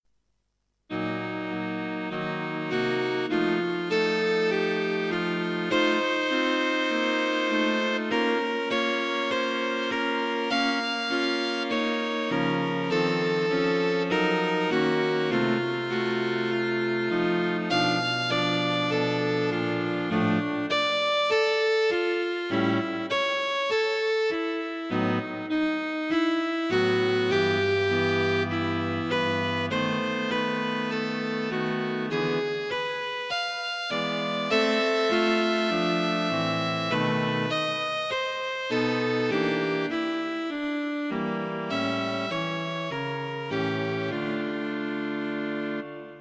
Flowing along at a leisurely pace.
Cello Quartet Flowing along at a leisurely pace.